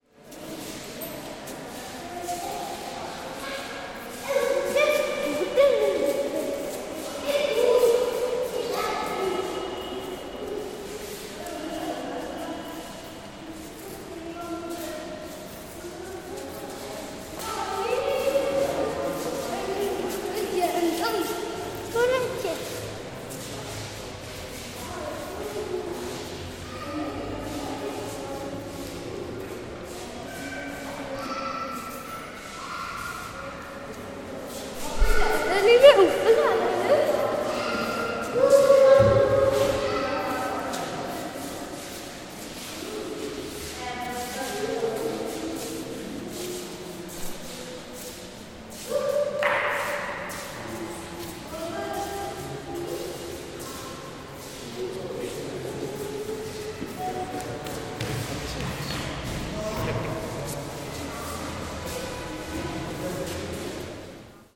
recording inside Indian Museum,Calcutta,India in March 2014